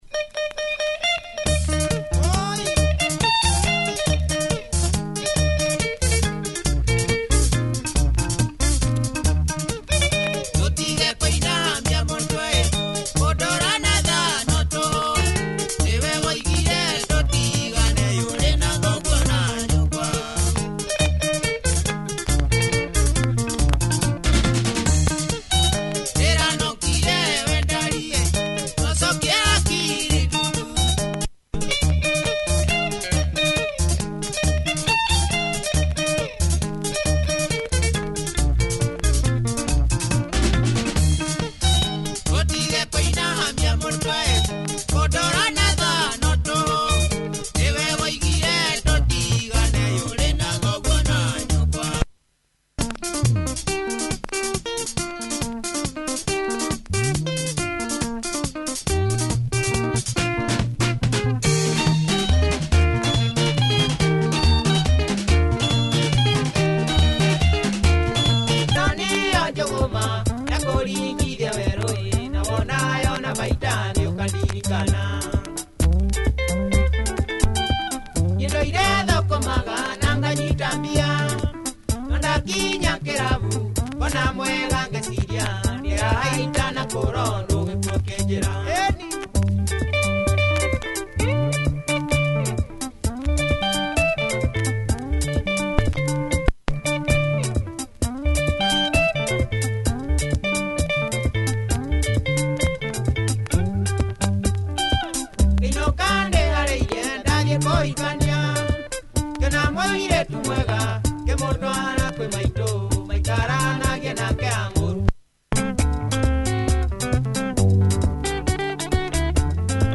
Strong Kikuyu Benga groover